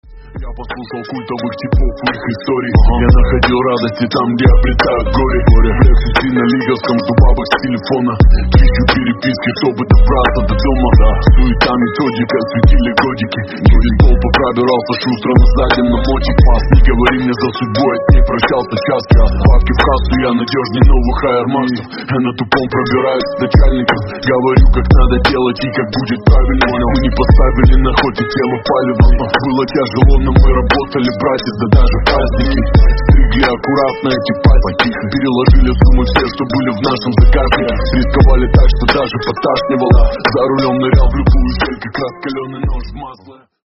громкие с басами гангстерские